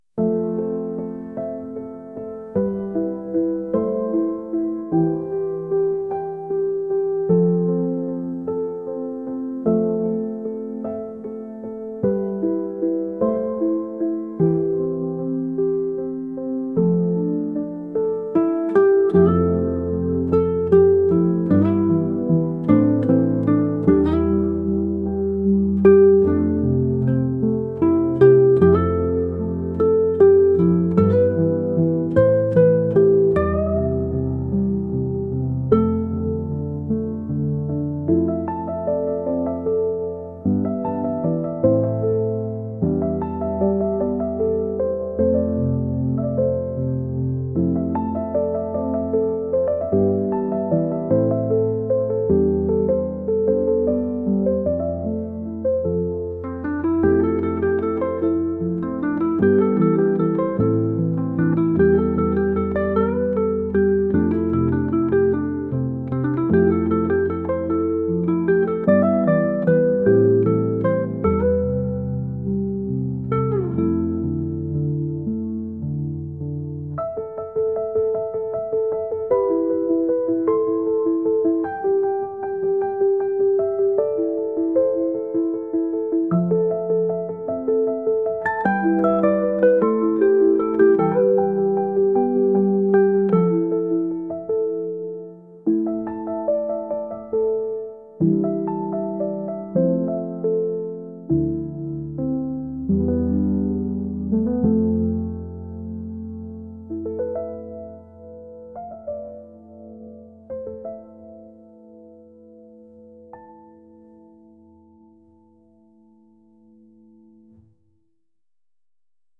言いたいことがあるけどなかなか切り出せないようなもどかしさを感じるようなピアノ曲です。